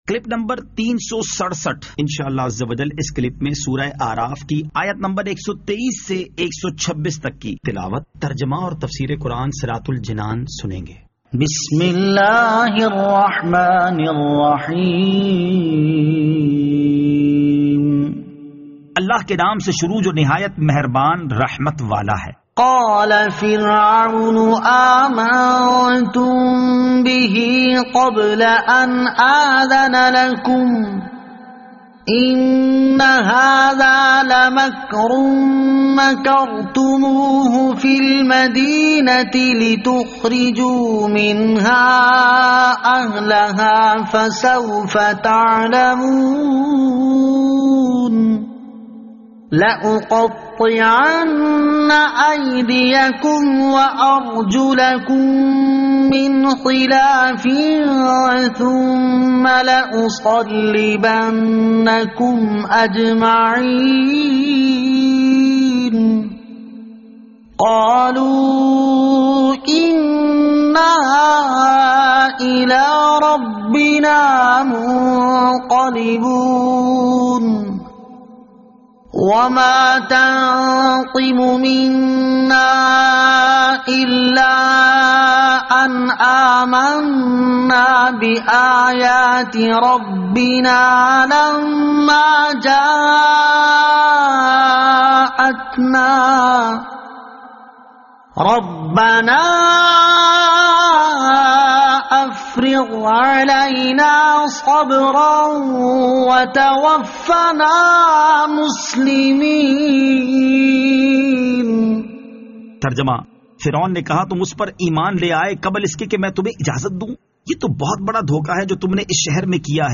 Surah Al-A'raf Ayat 123 To 126 Tilawat , Tarjama , Tafseer